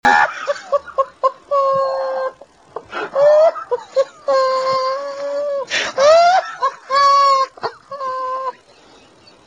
Risada Erik Hartman
Ataque de risos de Erik Hartman, personagem do programa de comédia de esquetes para a TV "In De Gloria" ou "Boemerang" da Bélgica.
risada-erik-hartman.mp3